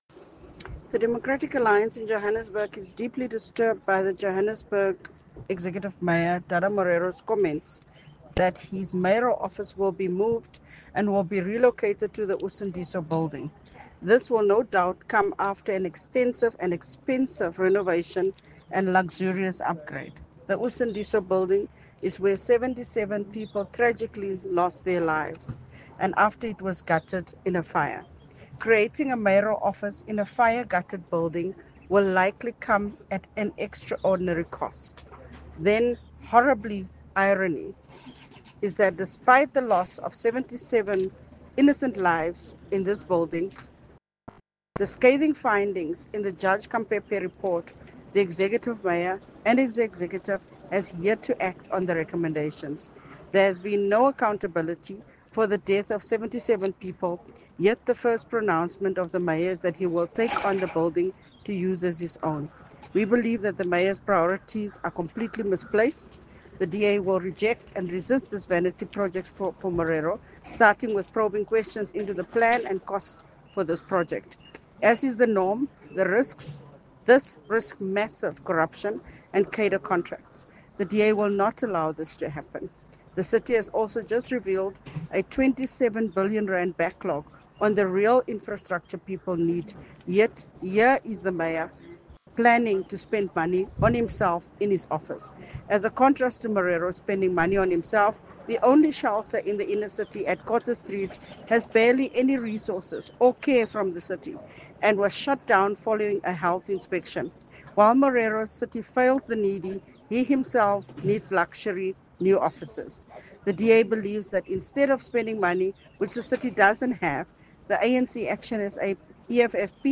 Note to Editors: Please find an English soundbite by Cllr Belinda Kayser Echeozonjoku